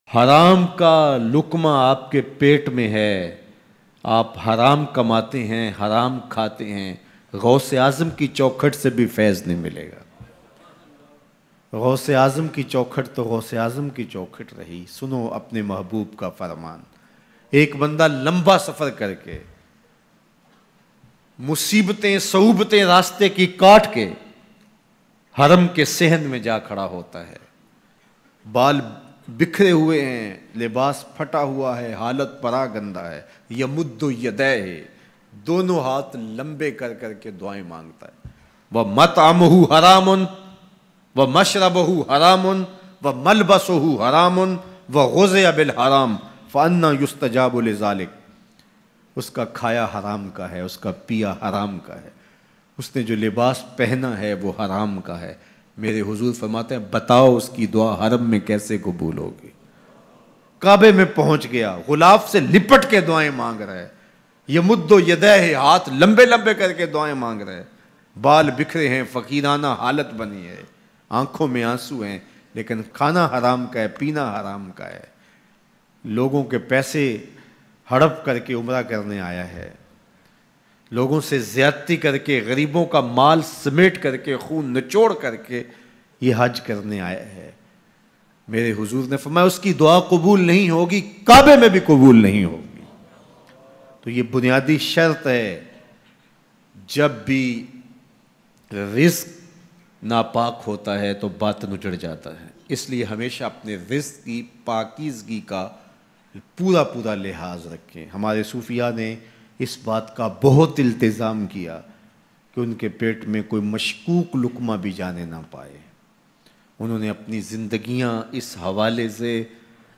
Harm-e-Kabaa me Rone wale ki Dua Bayan MP3